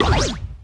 rhinodeath_02.wav